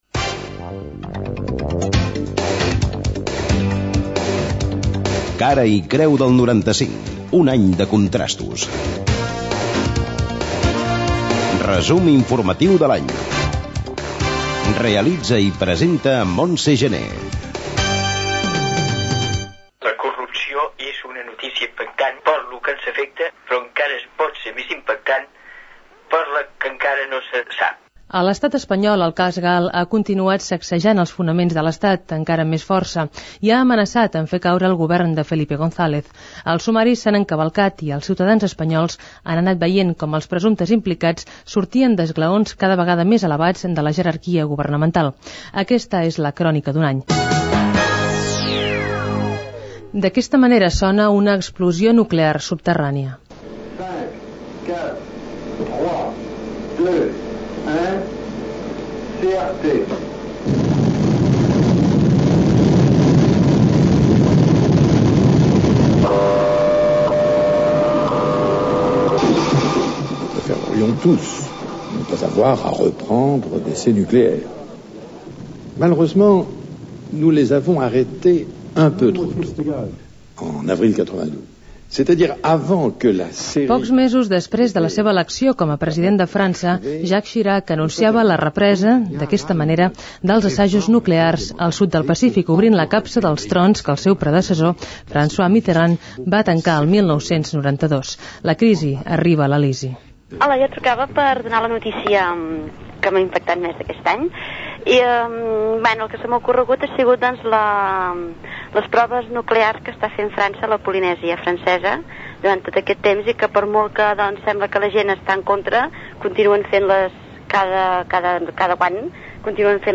Careta del programa dedicat a fer el resum informatiu de l'any 1995: el cas GAL, França torna a fer proves nuclears a la Polinèsia
Informatiu